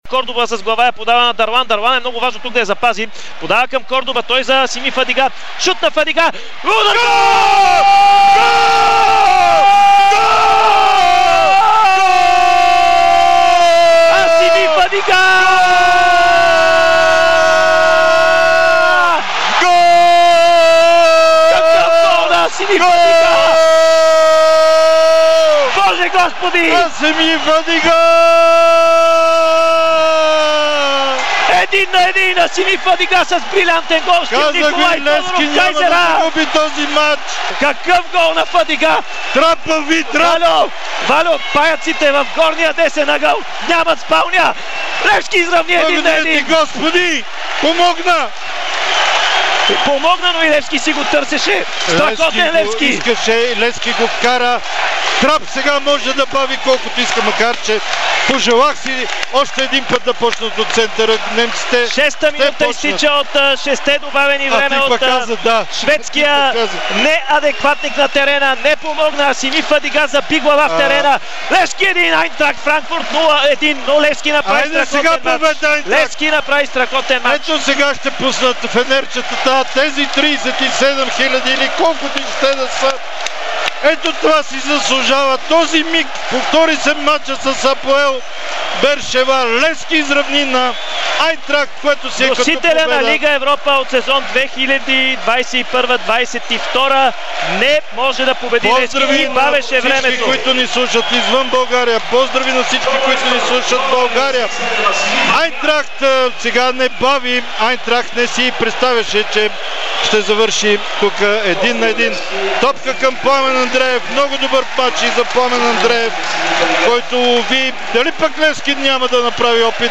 Екстаз в радиоефира